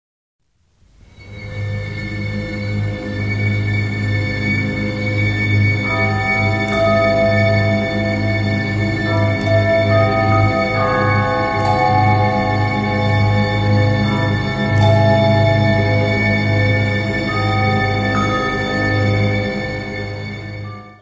Área:  Música Clássica
Adagio.